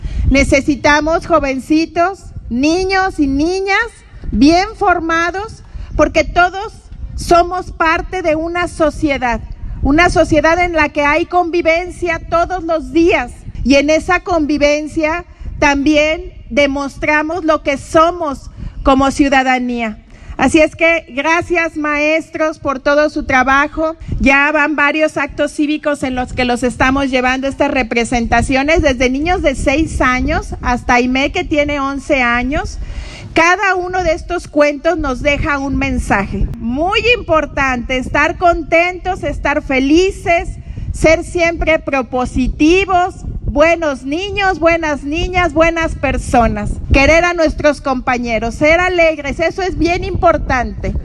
Lorena Alfaro García – Presidenta Municipal